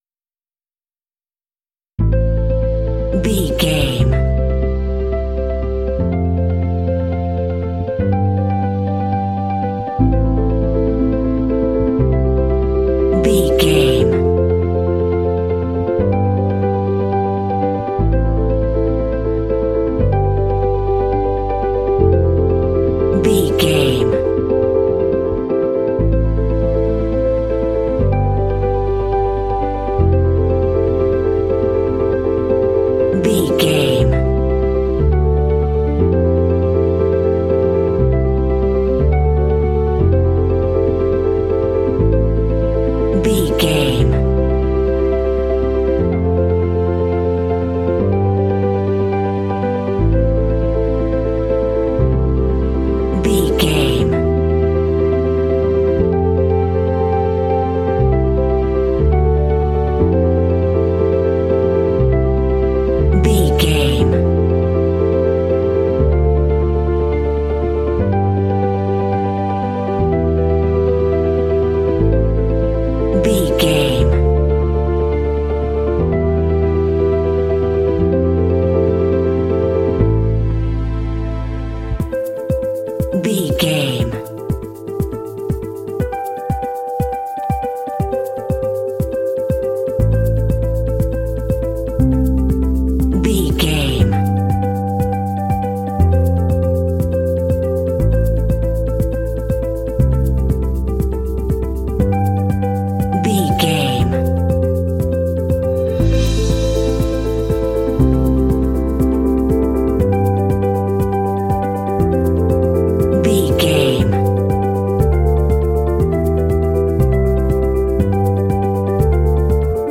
Aeolian/Minor
melancholy
contemplative
serene
peaceful
electric guitar
bass guitar
strings
drums
piano
ambient
contemporary underscore